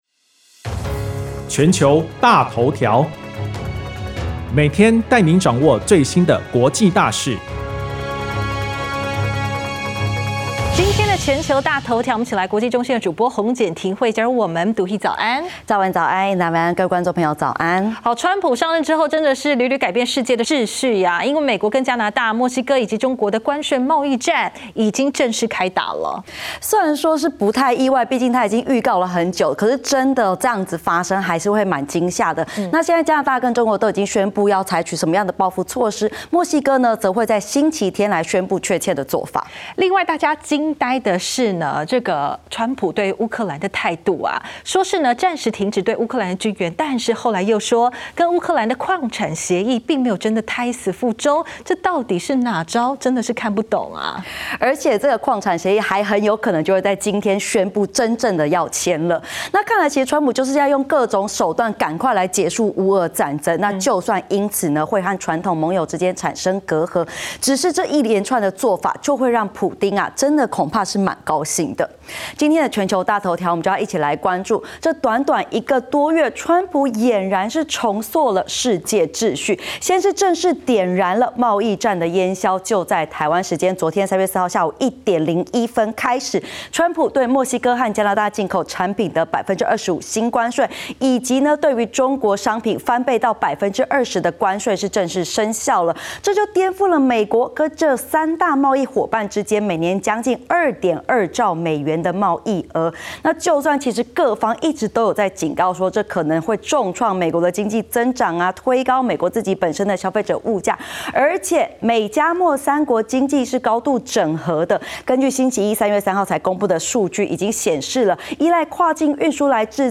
02:41 加拿大總理 杜魯道：所以就在今天(3/4) 美國向加拿大，他們最親密的夥伴、盟友、最親密的朋友發動了貿易戰，與此同時，他們正在討論要積極與俄羅斯合作，姑息撒謊成性的殺人獨裁者普丁，這樣的道理何在？